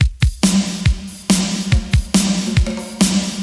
• 140 Bpm Eighties Punk Breakbeat Sample C Key.wav
Free drum loop sample - kick tuned to the C note. Loudest frequency: 2373Hz
140-bpm-eighties-punk-breakbeat-sample-c-key-V7h.wav